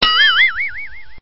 bgm-晕倒.mp3